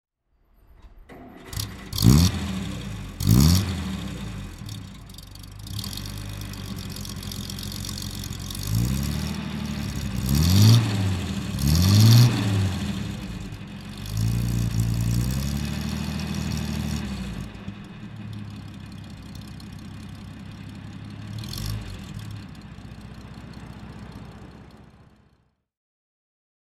REO Flying Cloud (1929) - Starten und Leerlauf